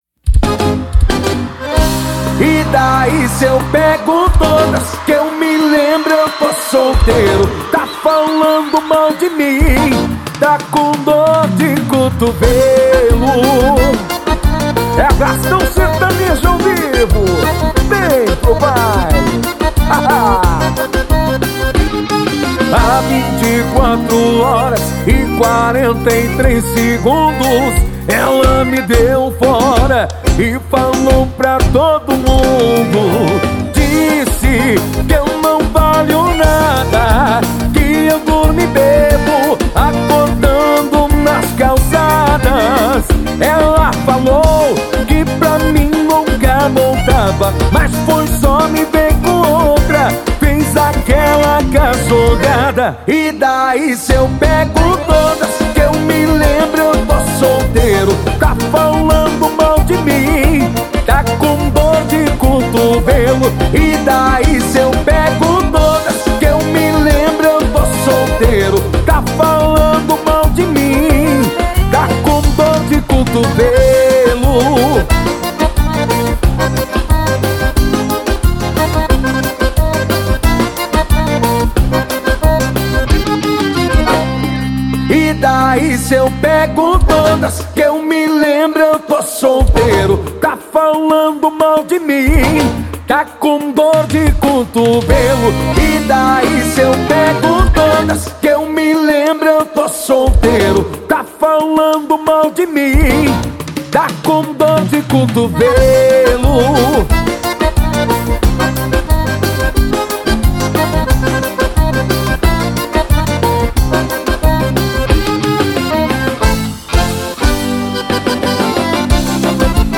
Composição: Cover.